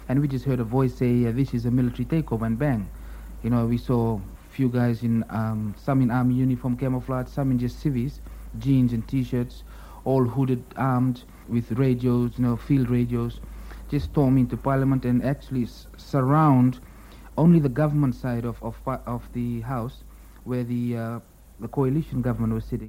My students recalled their personal experiences of the coup and  with portable tape recorders they interviewed a number of people in Suva. Their work was a 20 minute audio documentary about the day of the coup.
It begins with  a reporter  at  Parliament.